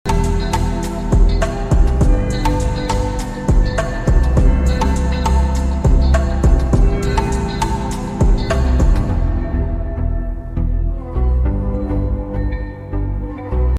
soothing instrumental version